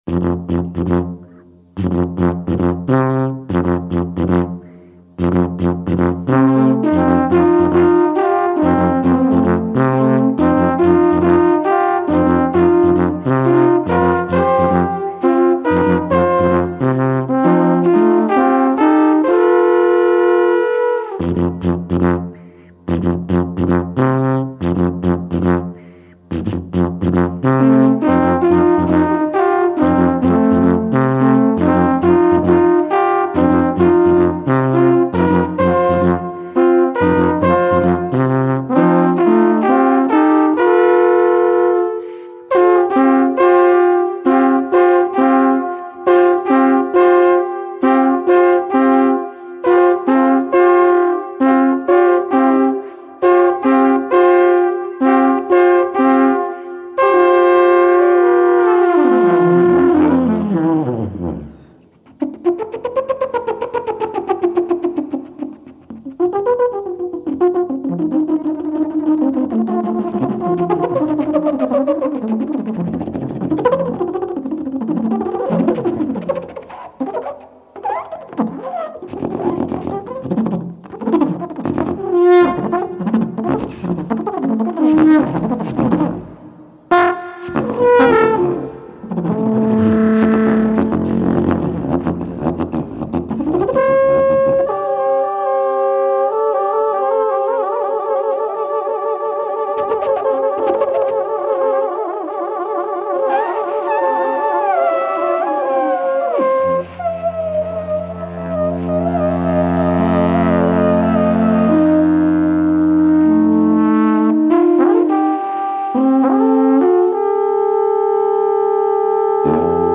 Imaginary Folk Music / Improvised Music.
alphorn trio